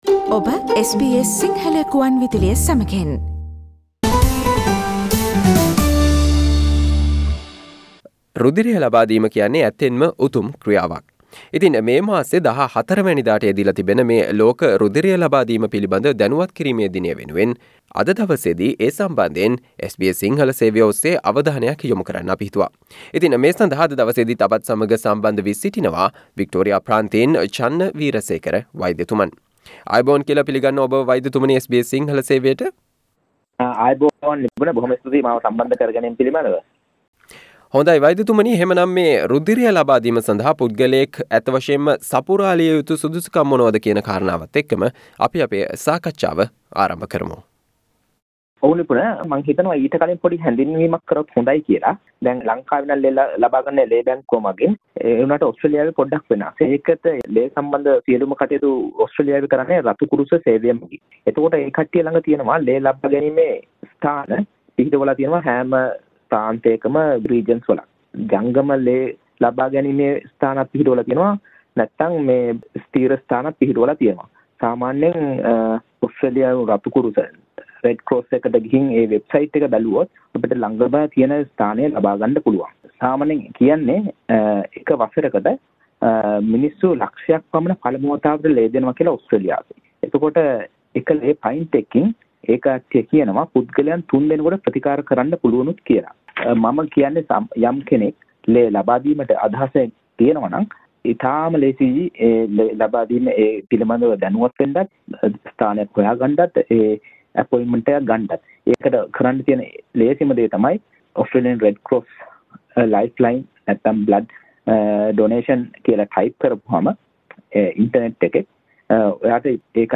You can listen to the discussion conducted by SBS Sinhala on the World Blood Donor Awareness Day which falls on the 14th of June.